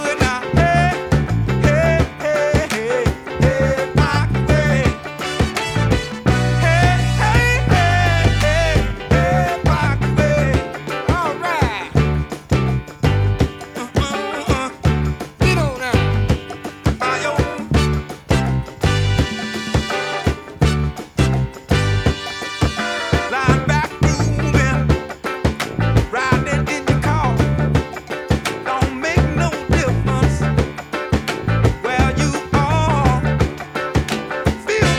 Жанр: Поп музыка / Рок / R&B / Соул / Фанк